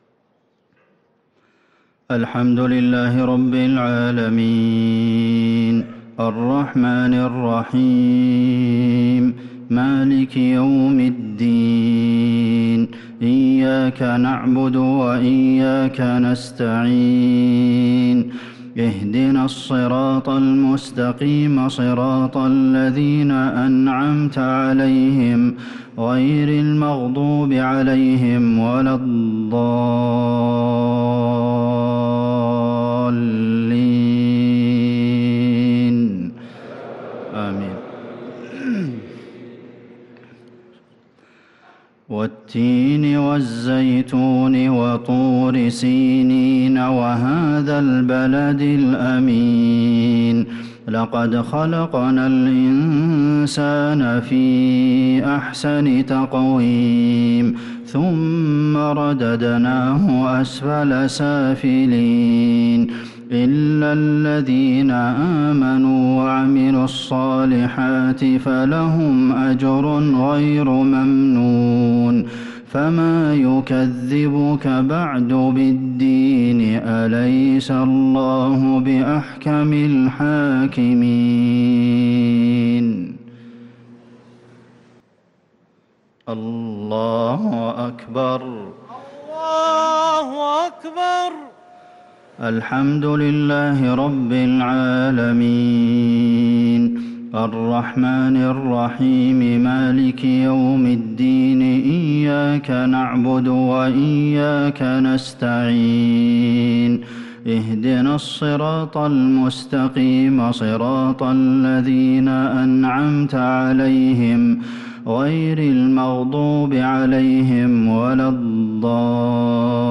صلاة المغرب للقارئ عبدالمحسن القاسم 23 ربيع الأول 1444 هـ
تِلَاوَات الْحَرَمَيْن .